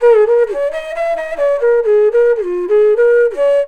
FLUTE-B02 -L.wav